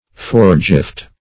Foregift \Fore"gift`\, n.